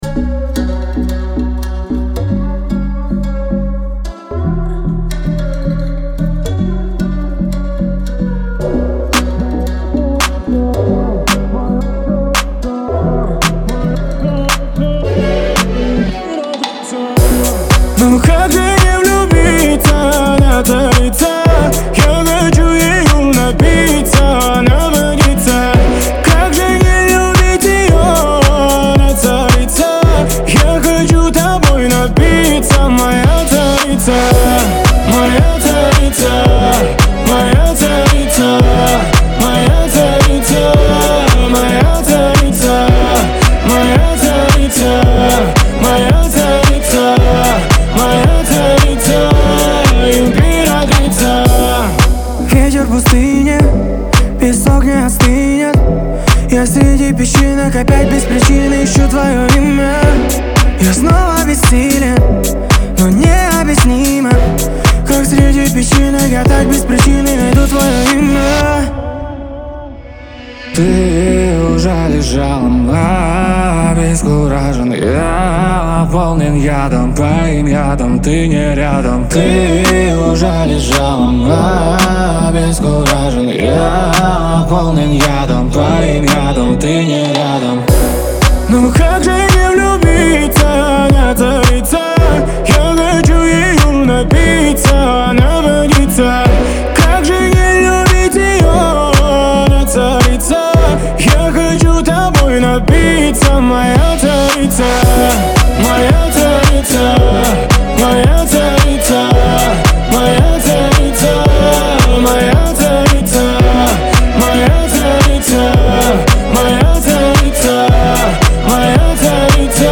это современный трек в жанре поп с элементами R&B